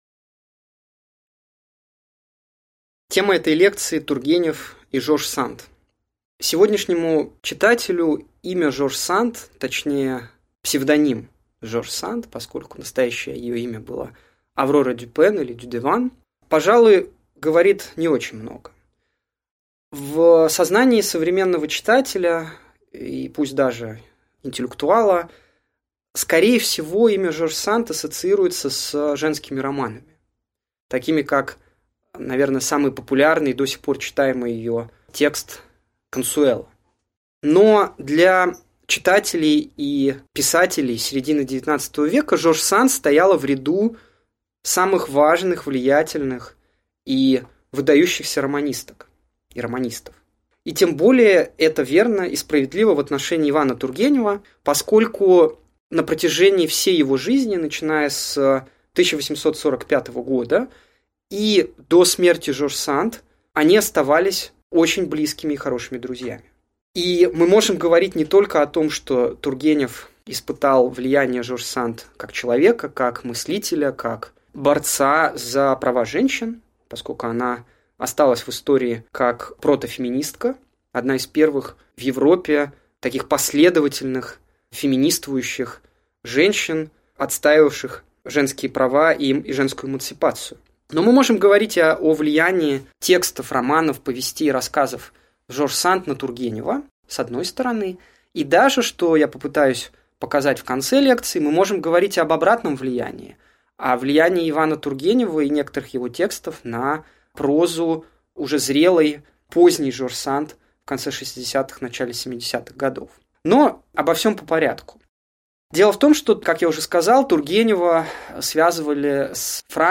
Аудиокнига Лекция «Тургенев и Жорж Санд» | Библиотека аудиокниг